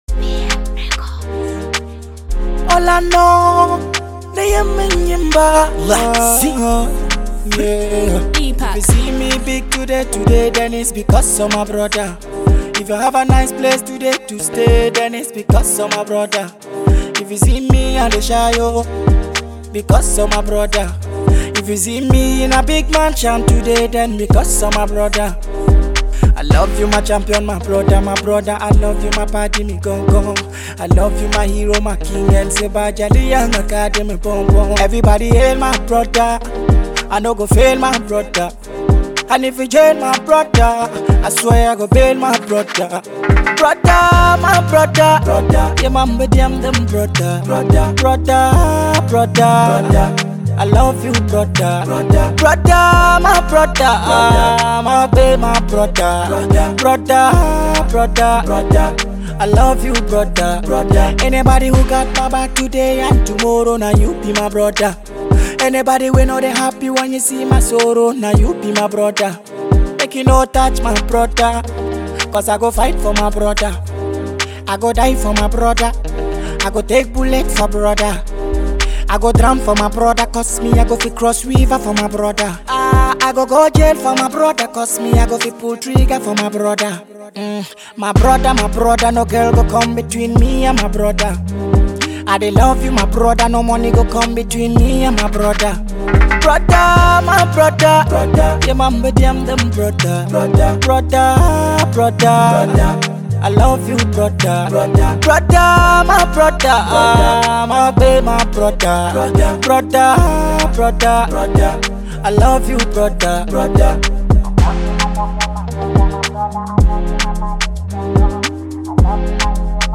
With heartfelt delivery and relatable storytelling
soulful voice
rich Afro-inspired melodies
is an Afro/Dancehall Artist from Ghana in West Africa.